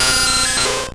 Cri de Nosferapti dans Pokémon Or et Argent.